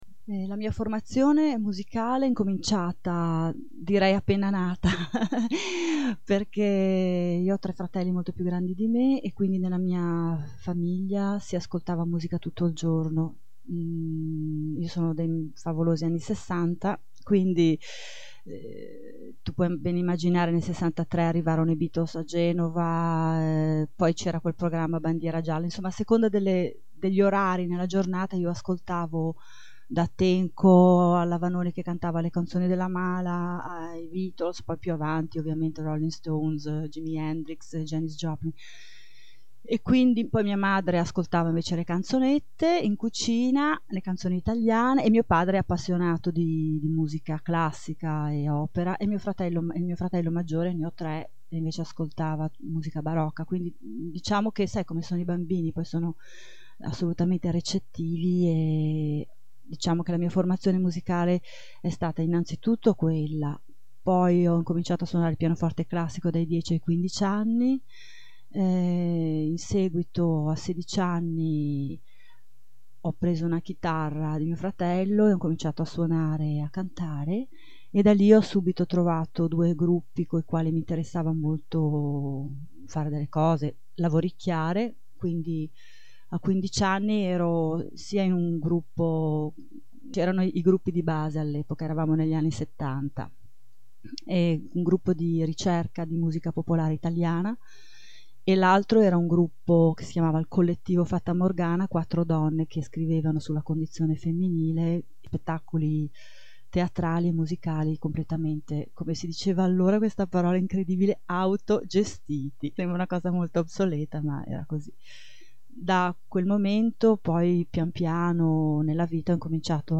Intervista a Radio Babboleo